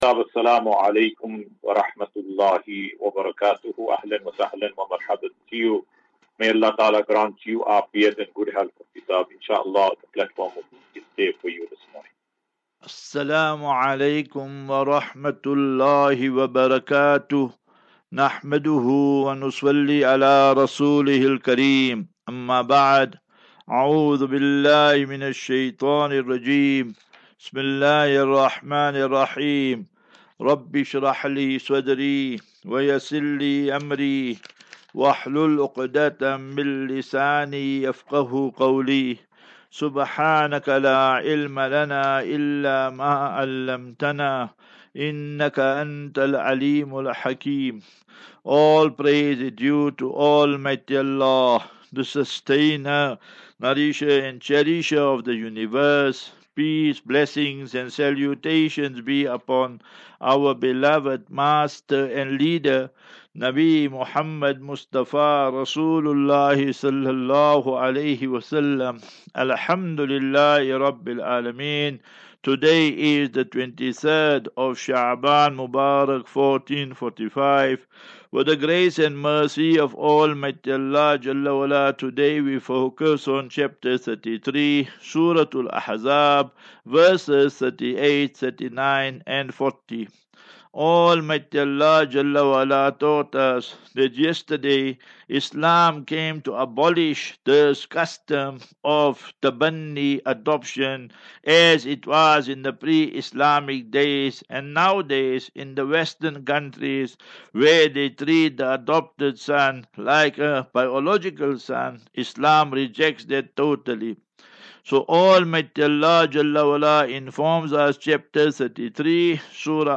Daily Naseehah.